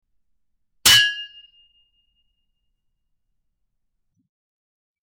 Metal Clank
Metal_clank.mp3